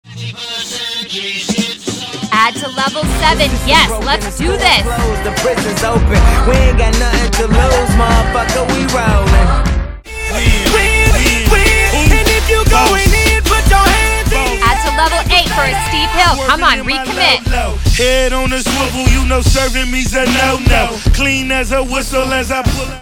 Runs, hills, tabatas, oh my! Luckily you have music from DJ Khaled, Eminem, Chris Brown and more to get you through.